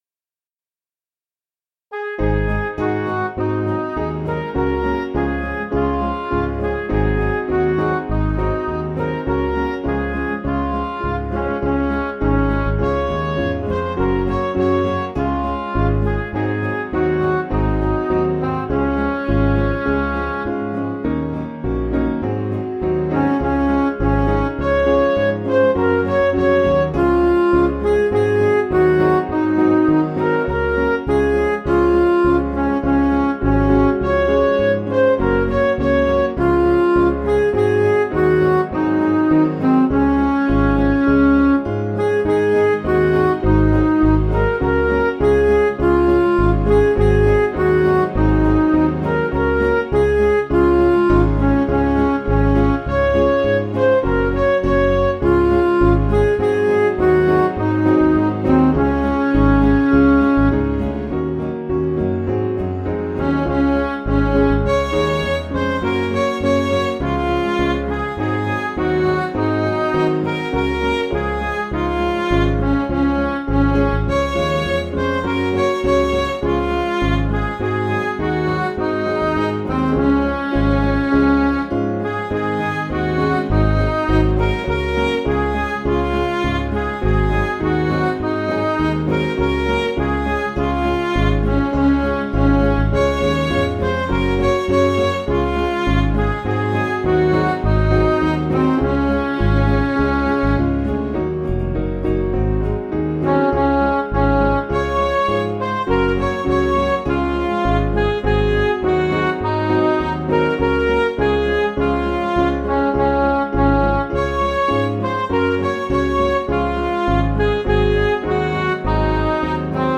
(CM)   4/Db
Midi